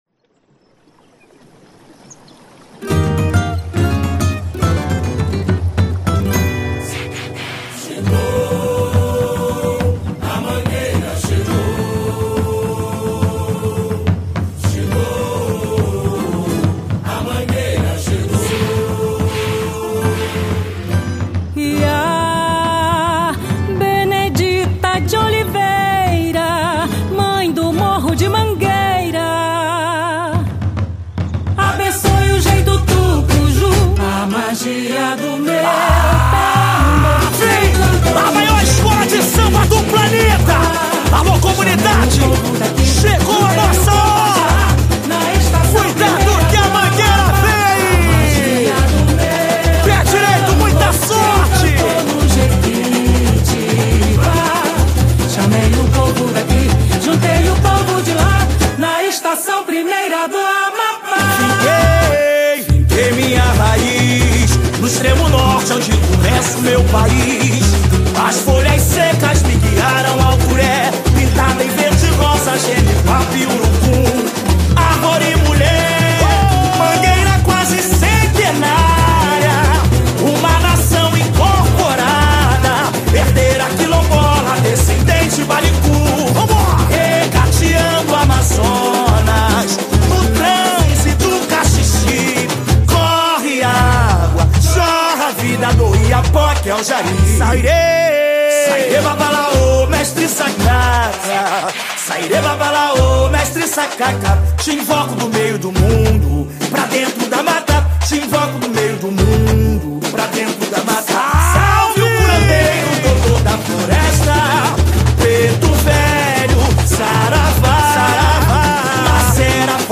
Samba Enredo 2026